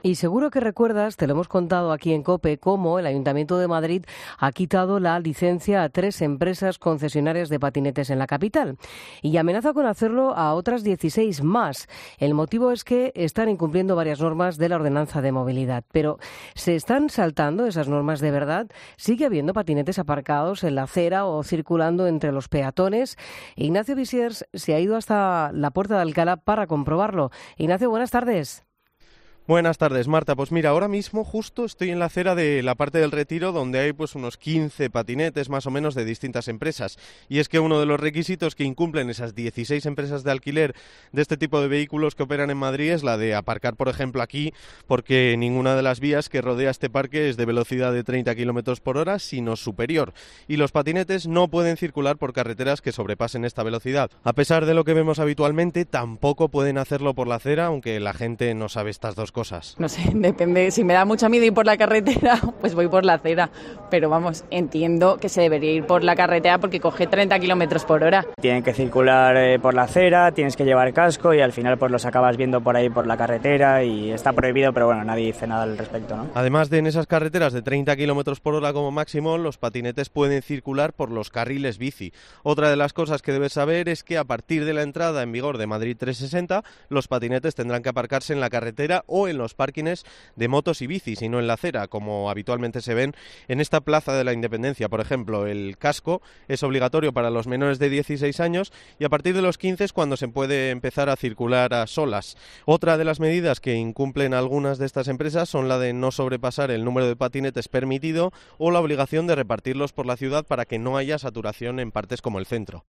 En COPE, hemos preguntado a varias personas a ver si sabían por donde había que circular con este tipo de vehículos.